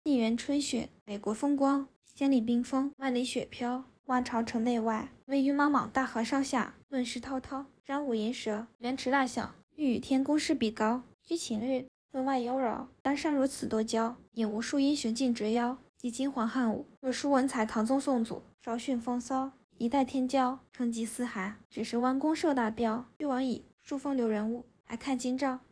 // VITS 模型初始化（推荐，模型较小）
点击试听流式TTS生成的"沁园春·雪"效果